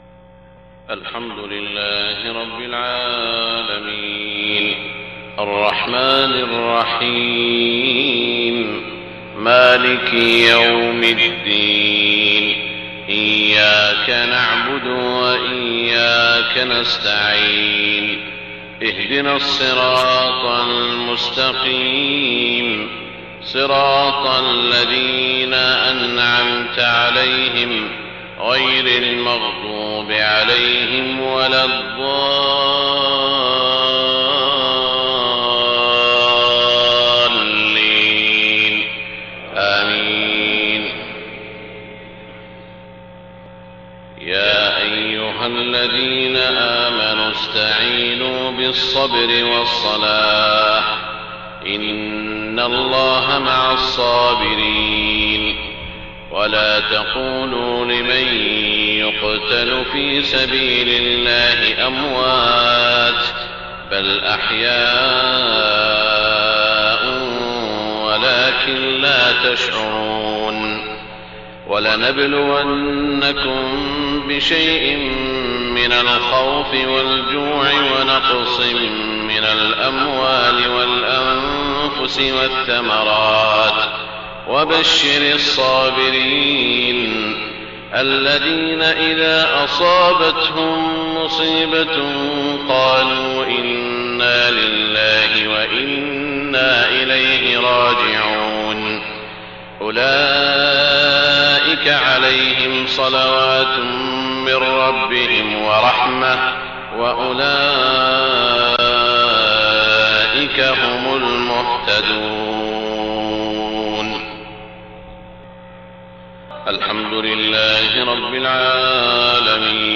صلاة الفجر 5 ربيع الأول 1430هـ من سورة البقرة 153-160 > 1430 🕋 > الفروض - تلاوات الحرمين